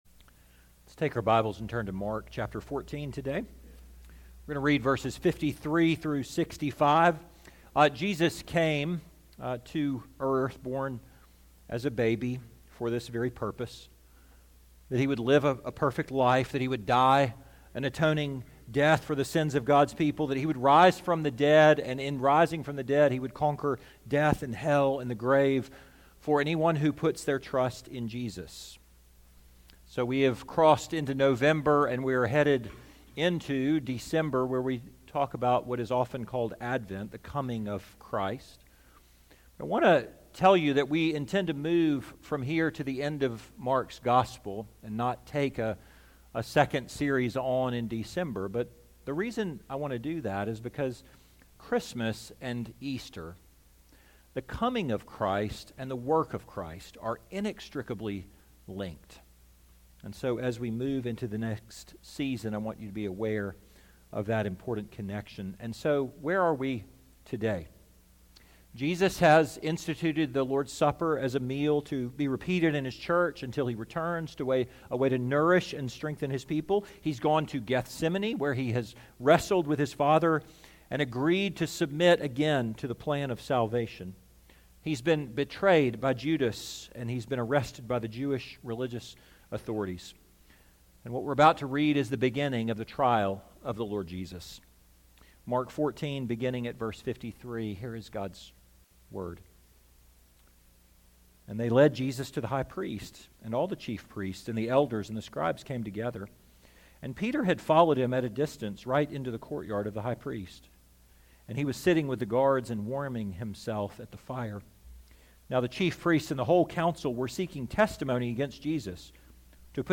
2024 The Silence of the Lamb Preacher